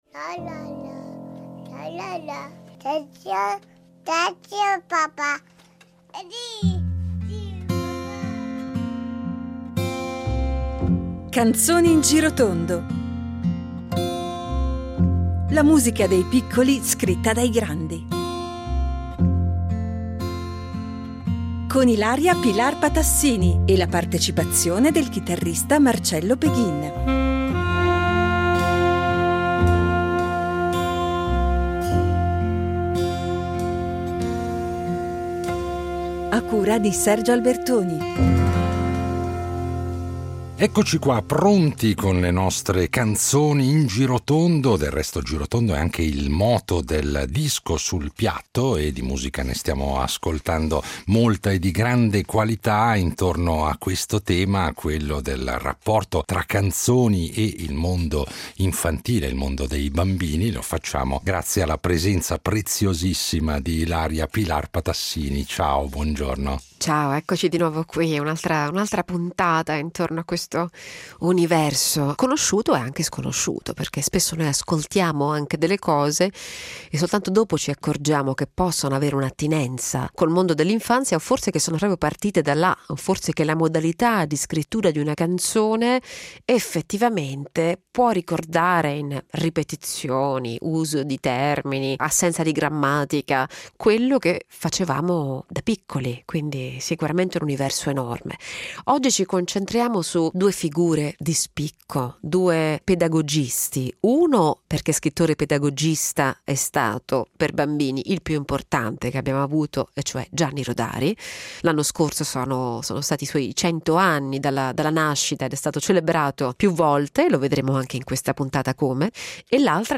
la partecipazione del chitarrista